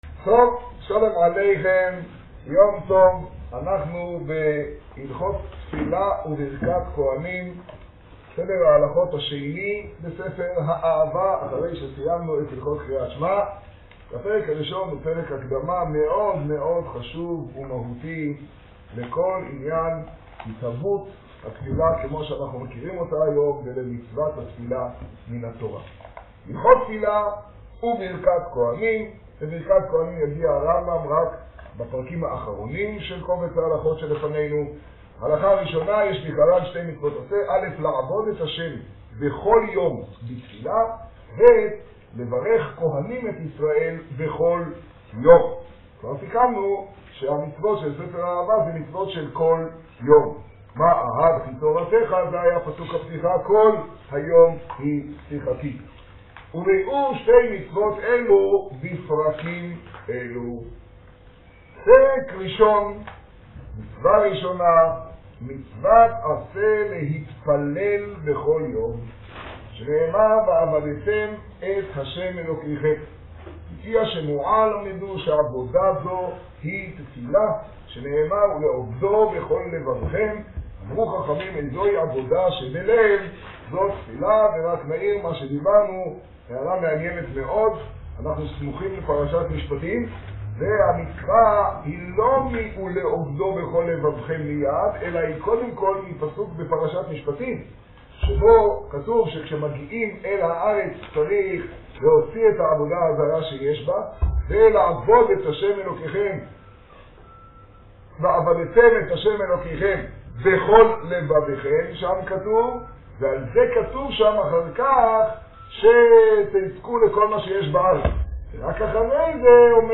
השיעור במגדל, כו שבט תשעה.